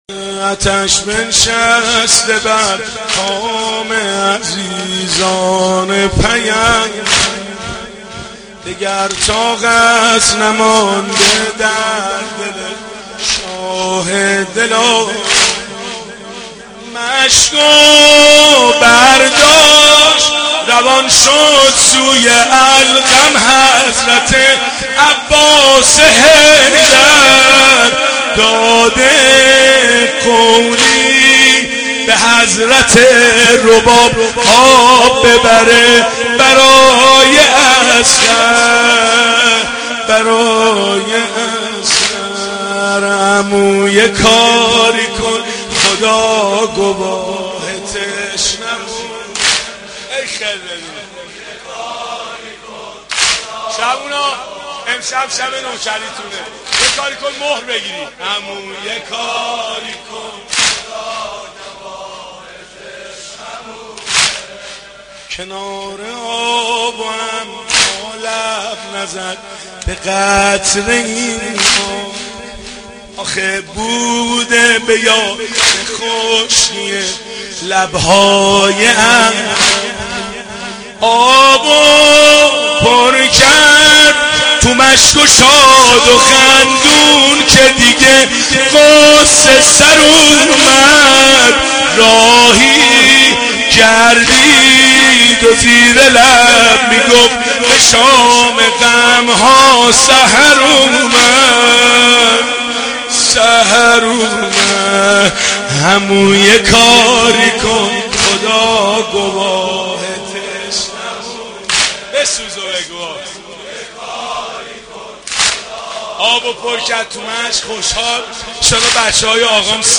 محرم 88 - سینه زنی 2
محرم-88---سینه-زنی-2